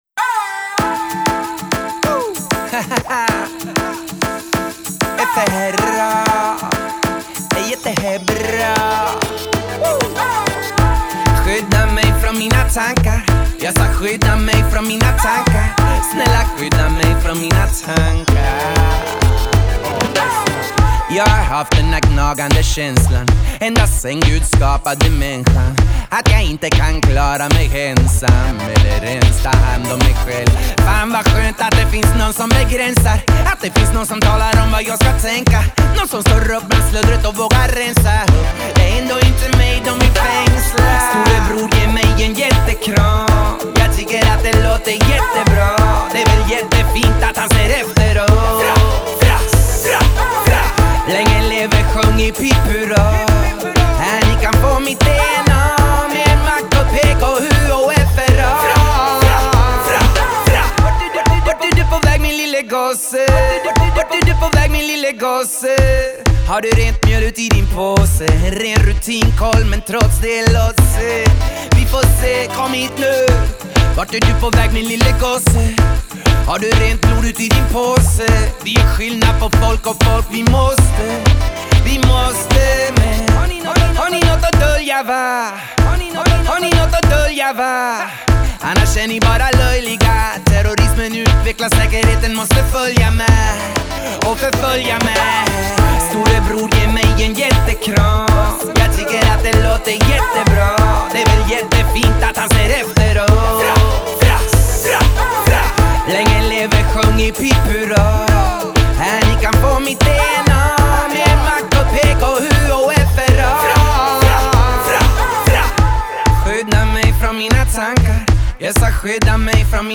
svänger